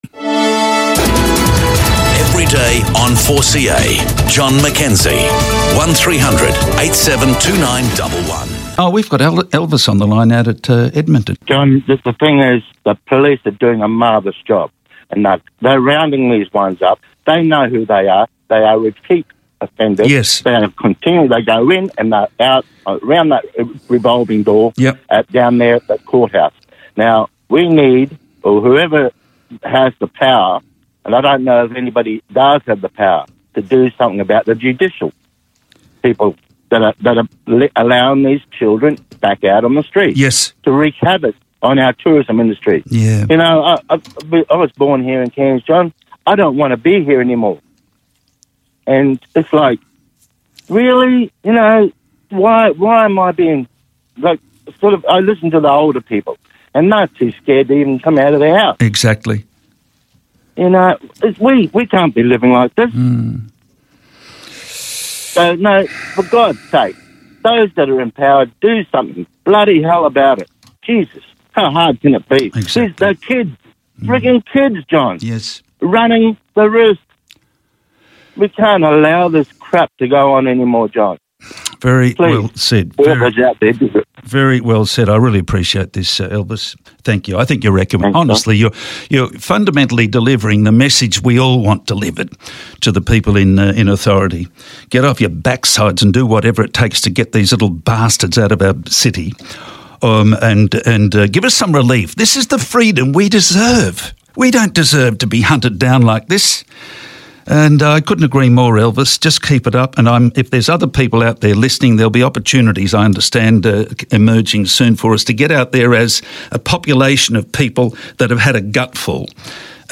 discusses the crime emergency in Cairns with two callers